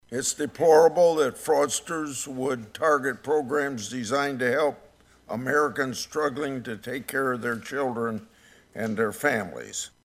IOWA SENATOR CHUCK GRASSLEY TALKED ABOUT THE ALLEDGED FRAUD IN MINNESOTA WEDNESDAY DURING A HEARING IN THE SENATE JUDICIARY COMMITTEE.